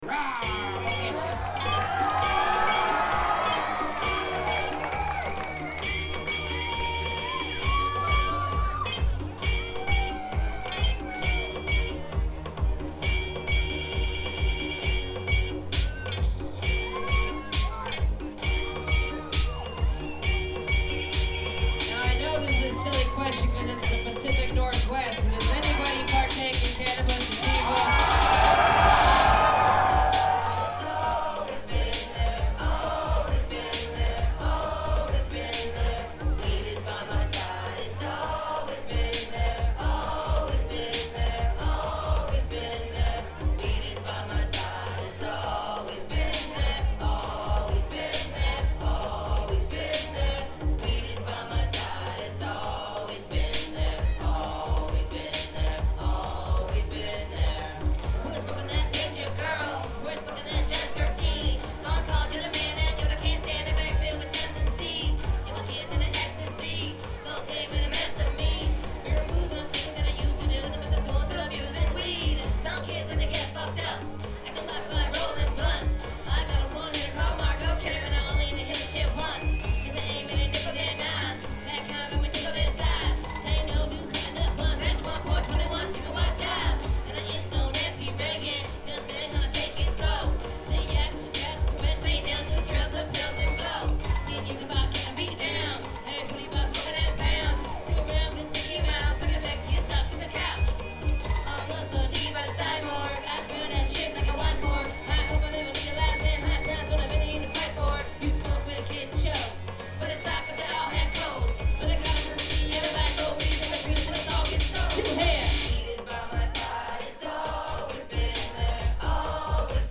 soundboard recording